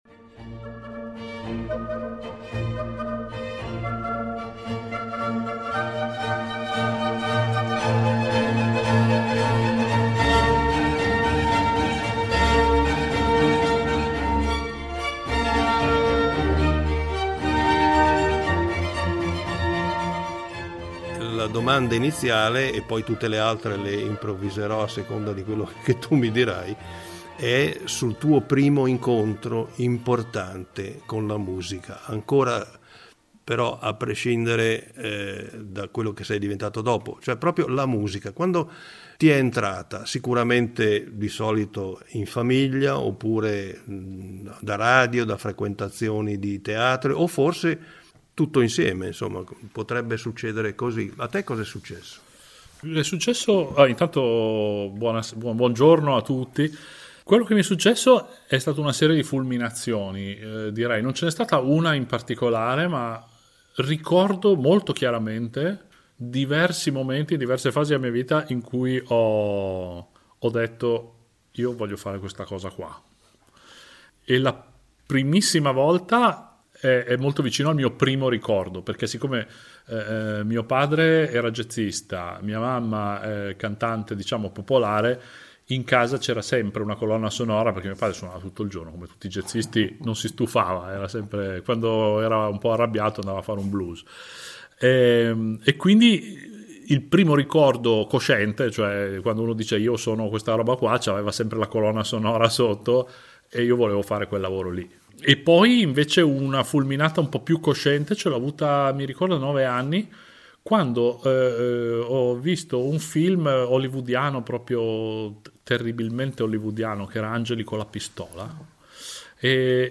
Un incontro